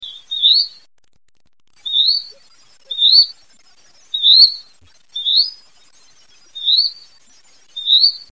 Hutton's Vireo (Vireo huttoni)
i. Song: double-noted zu-weep, with rising inflection, sometimes continuously repeated; vireo quality (P).
A ventriloquial chu-weem, chu-weem or zu-weep, zu-weep (L).
Slow song: